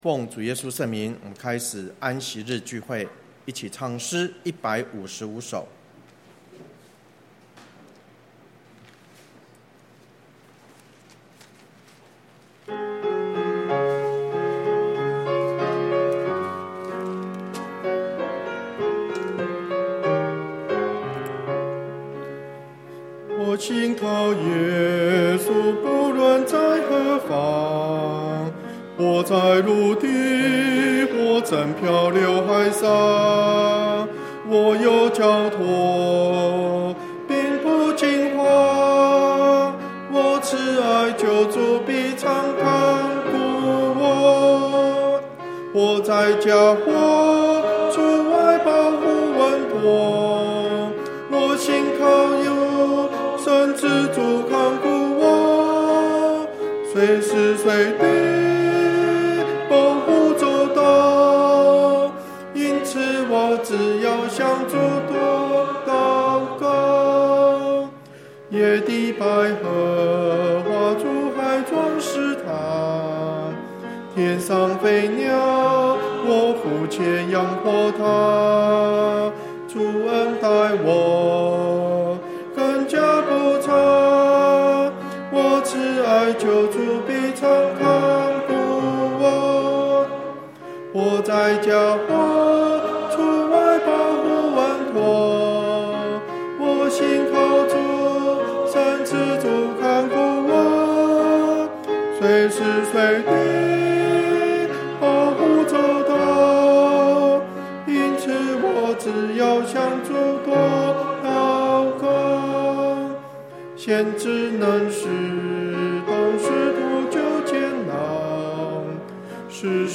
(聚會錄音)